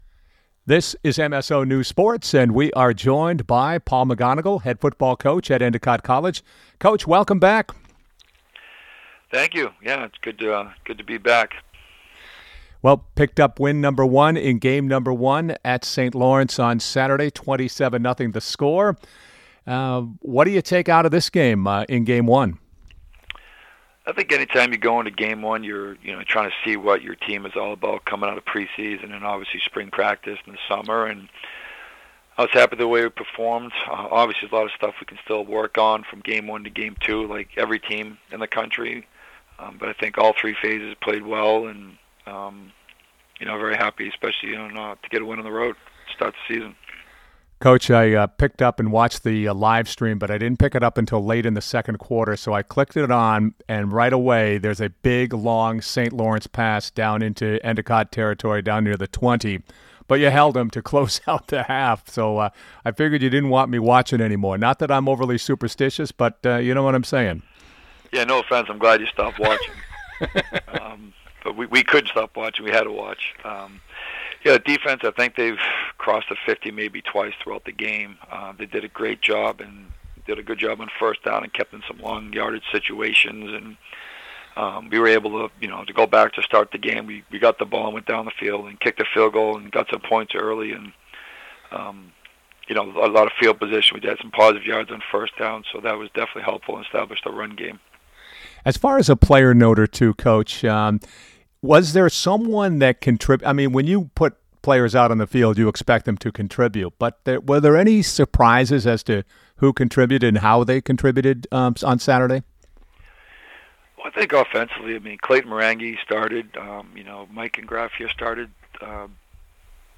BEVERLY (Podcast) The Endicott football team defeated St. Lawrence 27-0 on the road Saturday afternoon in Canton, New York.